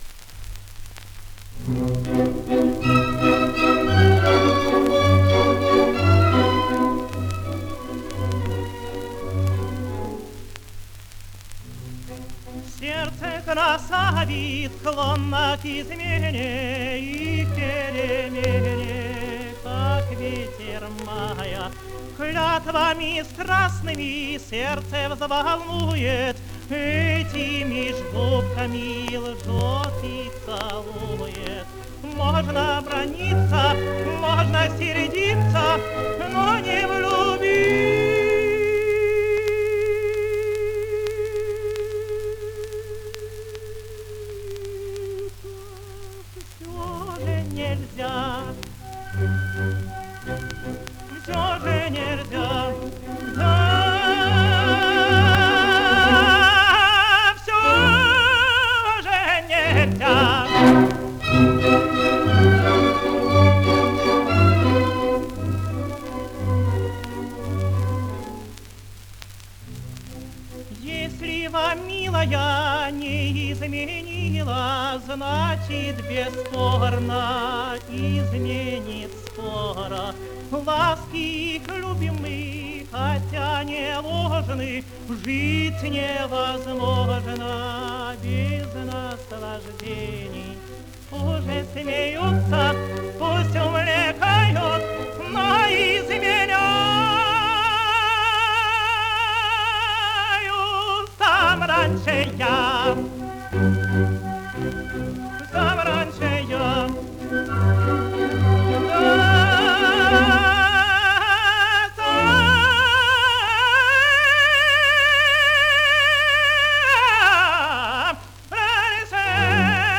Песенка Герцога. Оркестр Большого театра. Дирижёр А. Ш. Мелик-Пашаев.